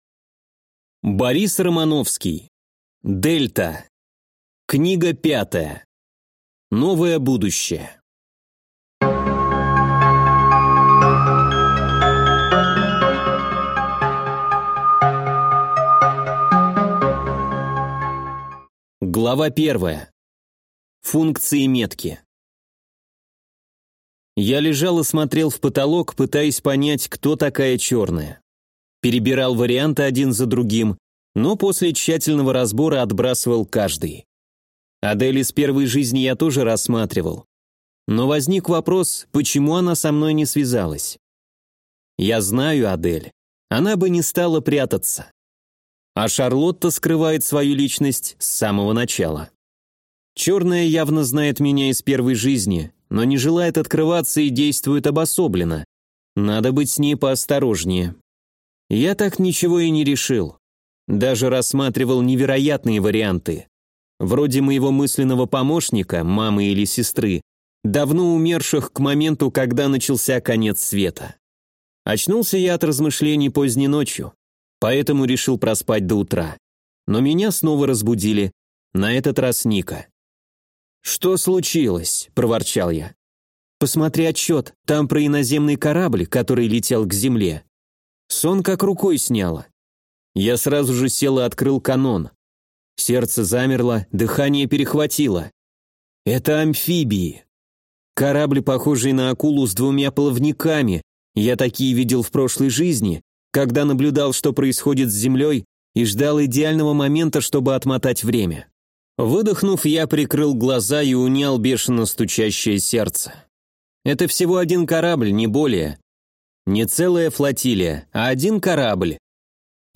Аудиокнига Дельта. Книга 5. Новое будущее | Библиотека аудиокниг